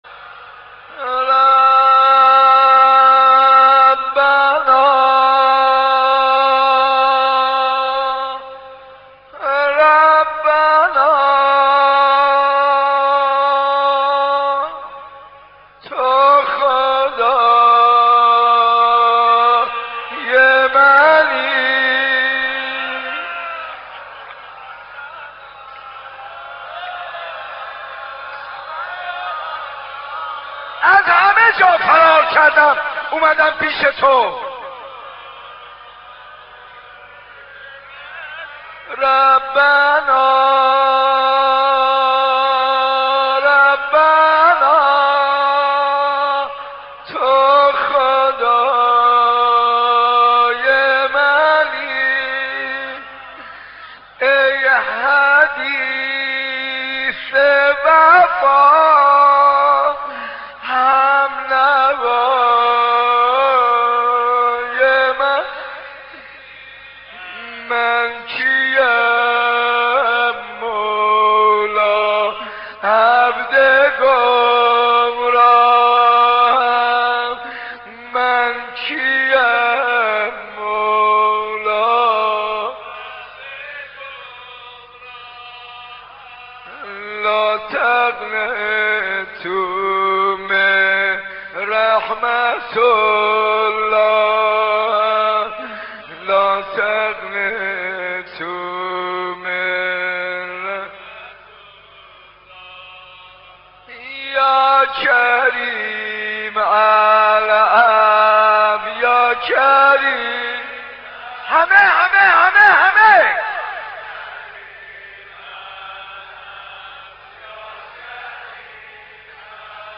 مناجات ویژه شب قدر با صدای حاج منصور ارضی